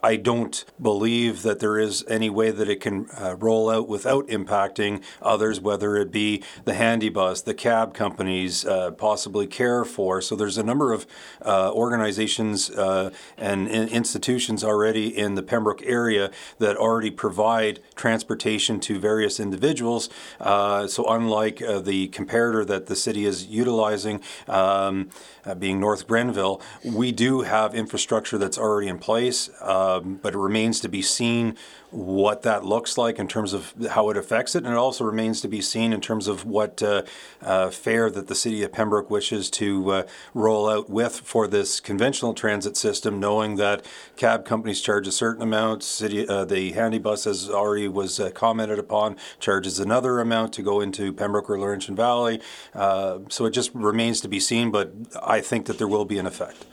Mayor Gervais says that there is going to be an effect but what that is will remain to be seen.